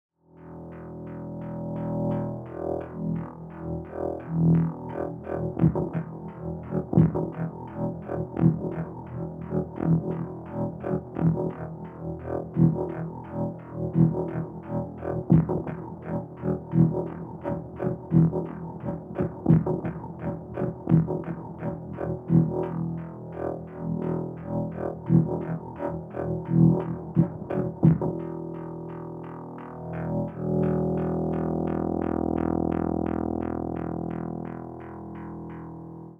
Here’s some direct audio examples of sliced WAV imports. DigiPRO sample playback on the Monomachine becomes way cleaner and more usable when we bypass slot normalisation and keep the original gain structure intact across a loop or single hit.
(1 drum hits, 2 break, 3 303, 4 break)